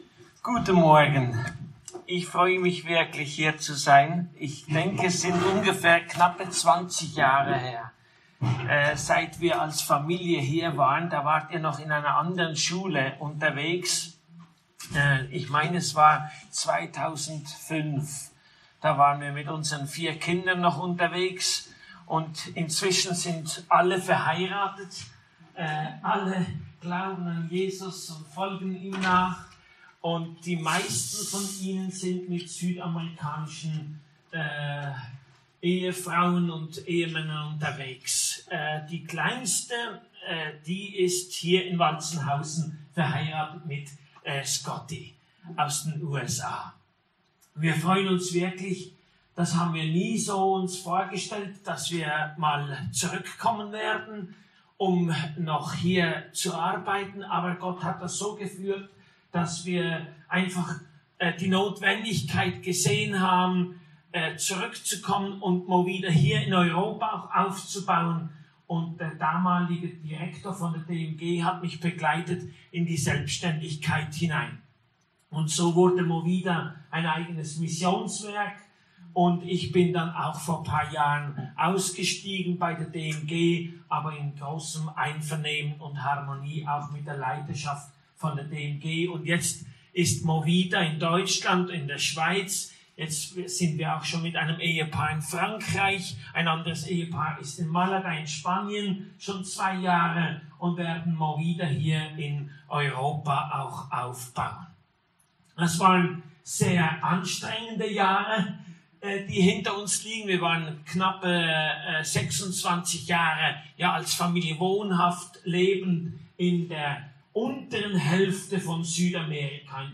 Gastpredigt